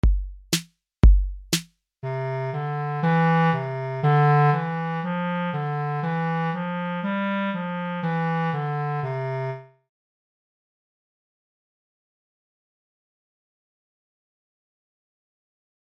Deseguido atoparedes as diferentes melodías para imitar empregando os vosos instrumentos.